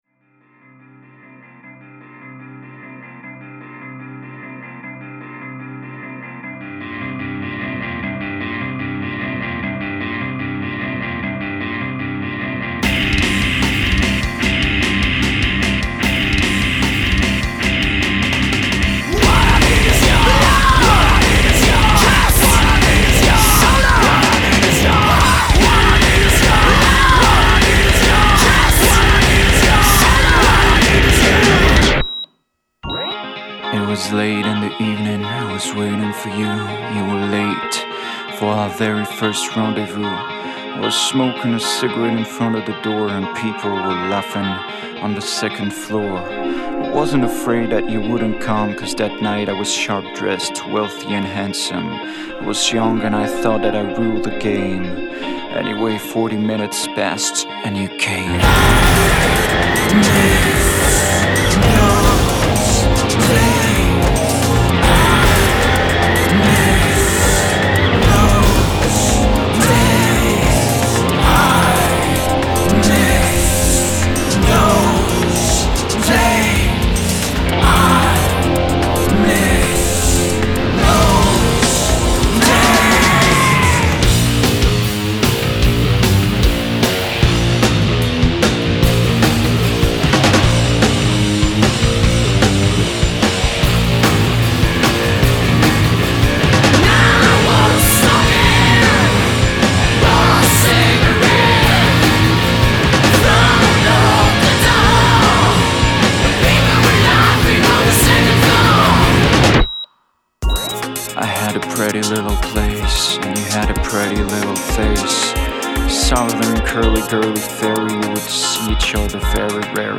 с вокалом